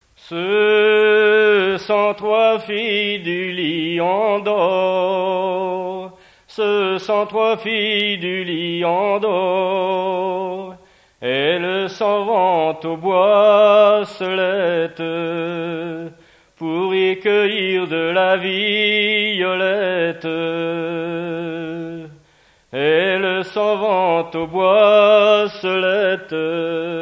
Genre strophique
chansons tradtionnelles
Pièce musicale inédite